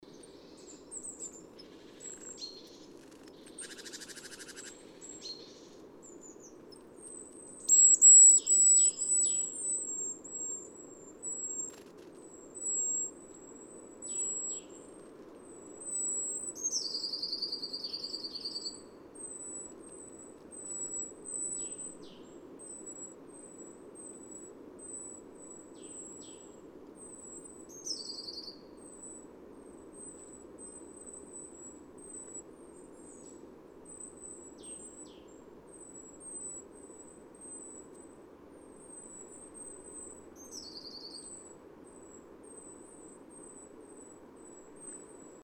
PFR07517, 130203, Great Tit Parus major, 4, excitement call, call, series of calls